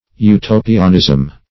Utopianism \U*to"pi*an*ism\, n.